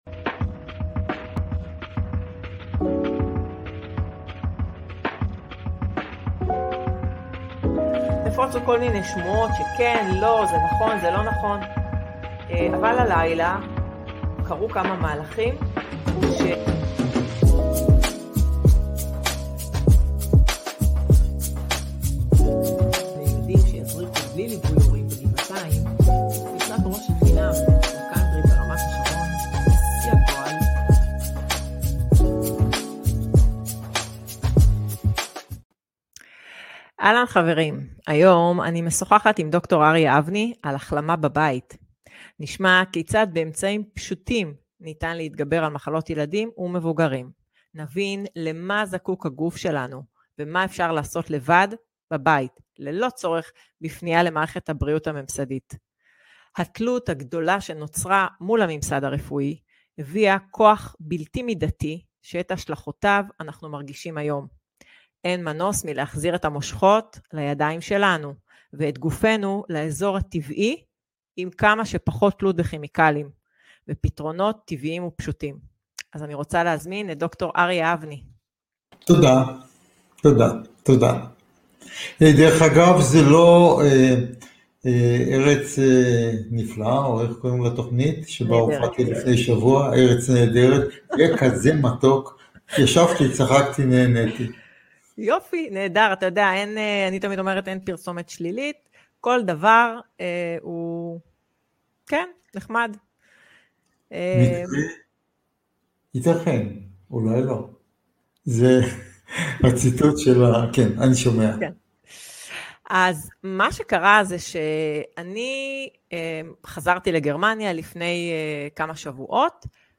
בשיחה